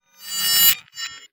time_warp_reverse_spell_03.wav